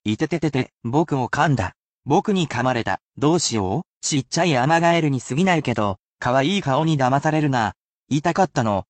Word of the Week is special in that you do not simply learn a Japanese word or phrase that I will pronounce for you personally, but you can absorb so many other morsels of knowledge.
[basic polite speech]